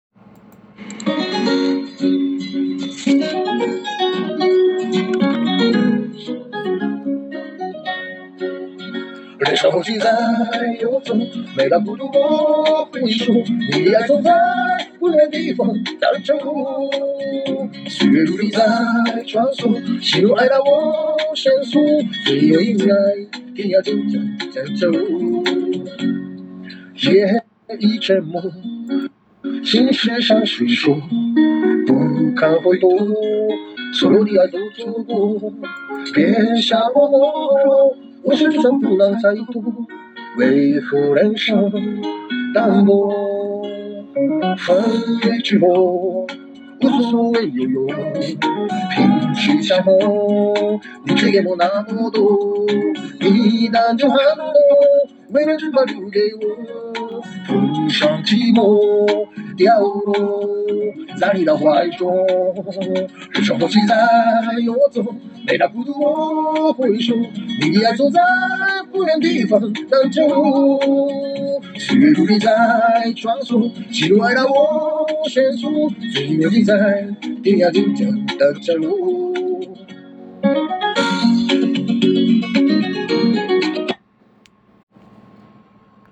歌唱コンテスト結果発表
・沧桑中带着怀念，娓娓道来
・我被这个男人的歌声所感动，他的歌声触动了我的心。
・很有复古感，有点粤语腔调的感觉，可以尝试以下粤语歌（赞）。